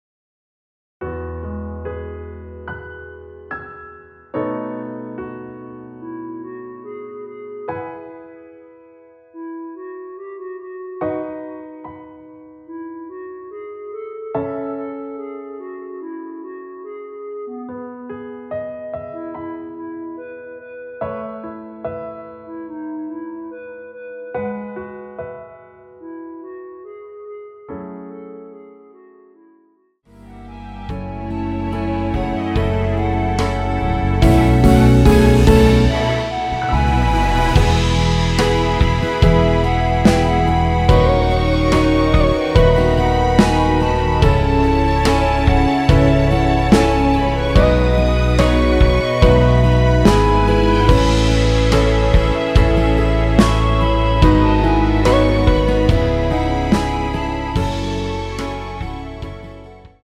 원키에서 (+4)올린 멜로디 포함된 MR 입니다.
노래가 바로 시작 하는 곡이라 전주 만들어 놓았습니다.
6초쯤에 노래 시작 됩니다.(미리듣기 참조)
앞부분30초, 뒷부분30초씩 편집해서 올려 드리고 있습니다.